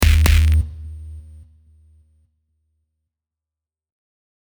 Error 5.mp3